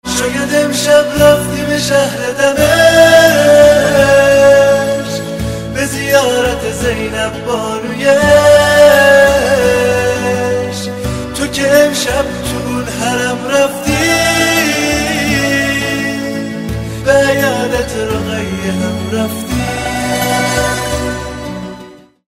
زنگ موبایل
رینگتون محزون و باکلام